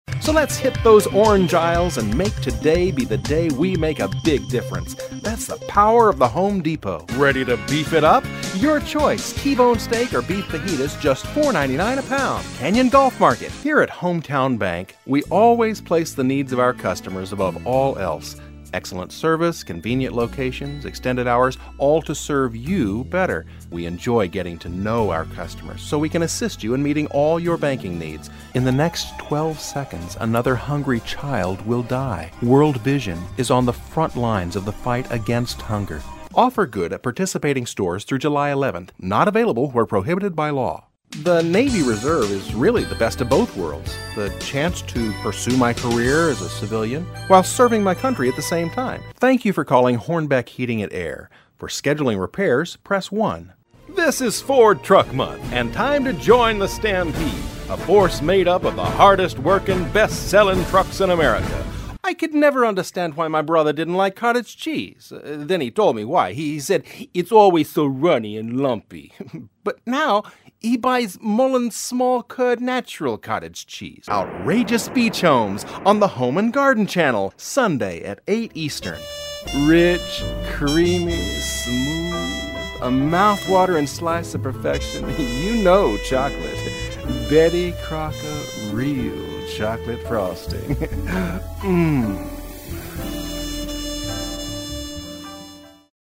Voice over, Unaccented American English, Narrator, Corporate, Commercial, Private Recording Studio, clear, friendly, natural
Sprechprobe: Werbung (Muttersprache):
A natural, warm, clear, personable voice, adaptable to many types of projects! Can be vibrant and fun, with a wry sense of humor and lighthearted attitude; or firm and direct with a strong voice of authority.